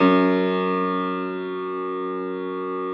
53g-pno04-F0.wav